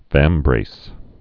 (vămbrās)